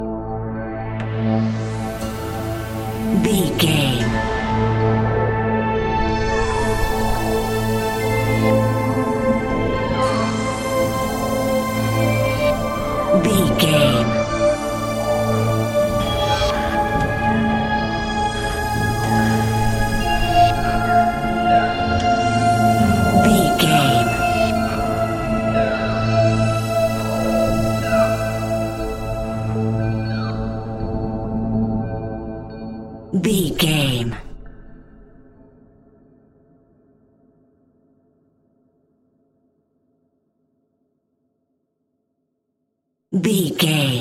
Aeolian/Minor
ominous
dark
suspense
eerie
piano
percussion
strings
synthesiser
atmospheres